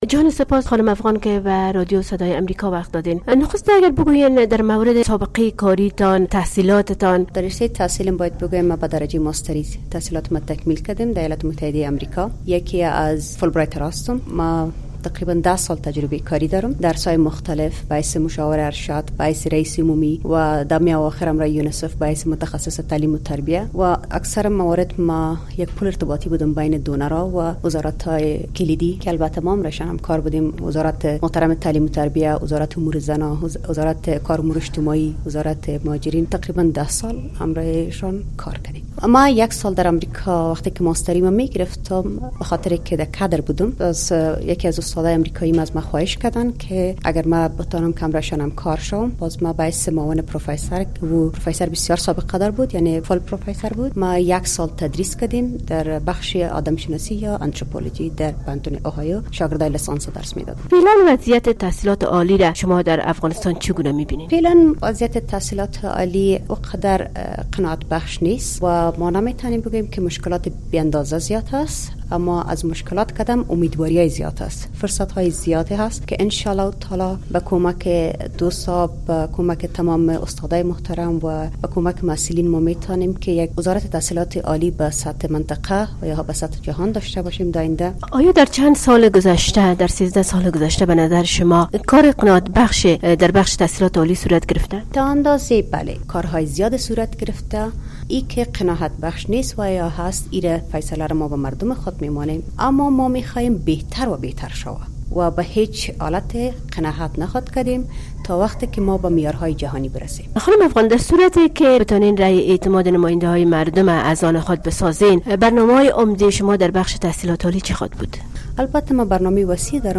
مصاحبه ها